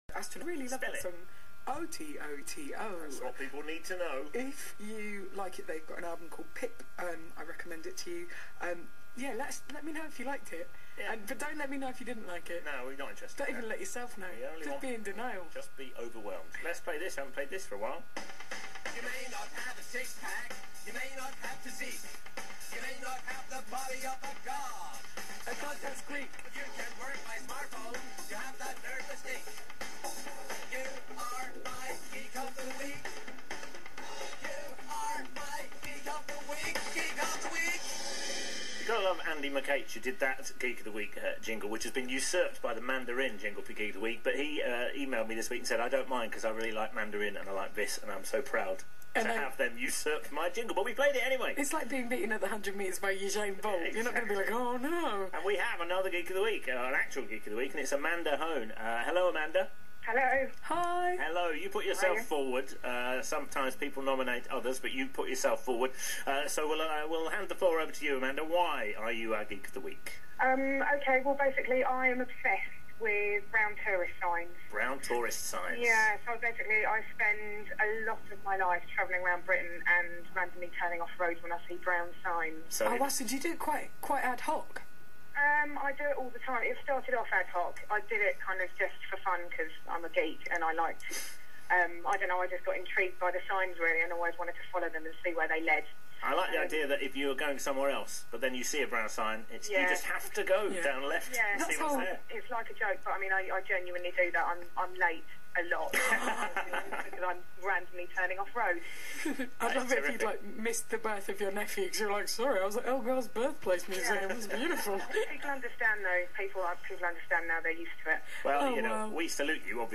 Me being the Geek of the Week on BBC 6 Music